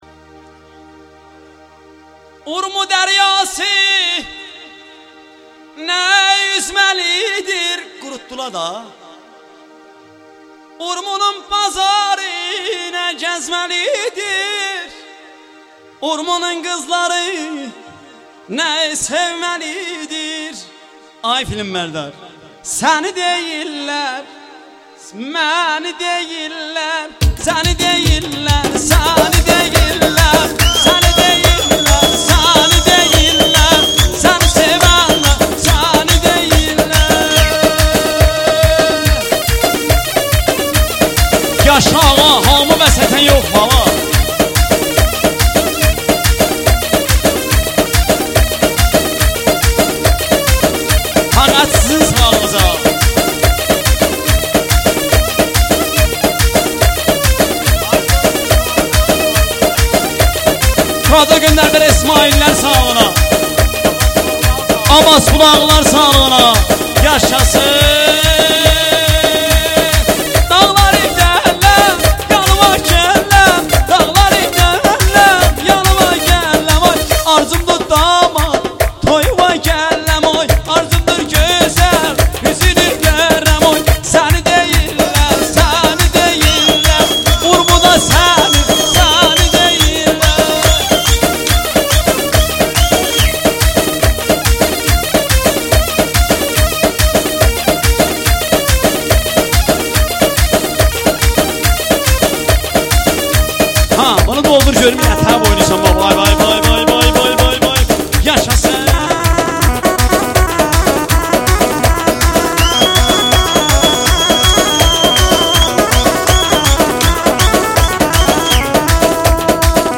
دانلود آهنگ آذری
دانلود آهنگ شاد
آهنگ چالش رقص ترکی